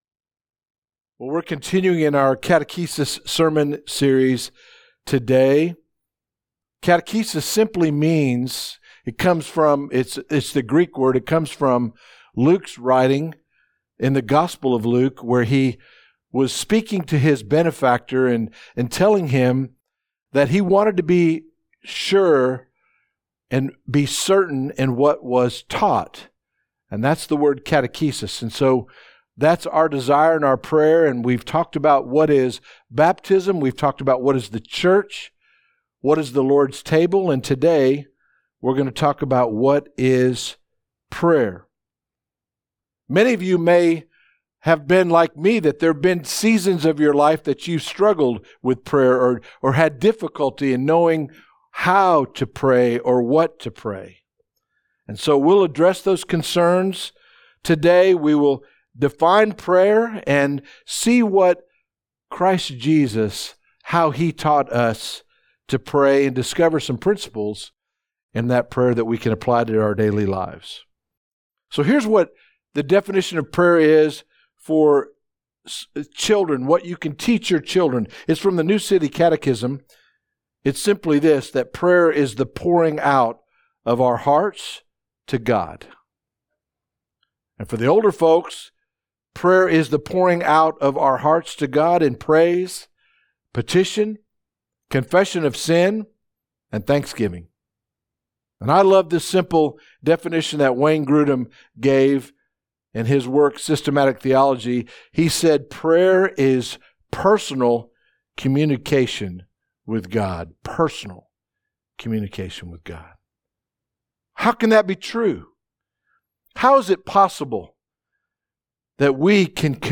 Remember His Provision Hutto Bible Church Sermons podcast